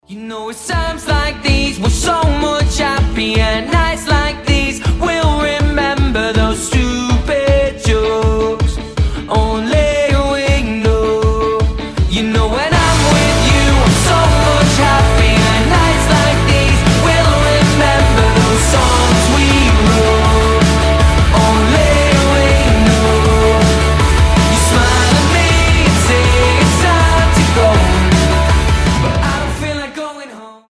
• Качество: 320, Stereo
позитивные
Pop Rock